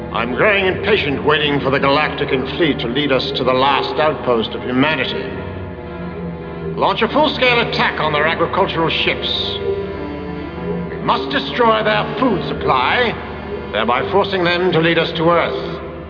In fact, the Leader's voice does sound vaguely familiar as some actor I've heard before elsewhere.
Here is the entirety of Imperious Leader's dialog in this episode: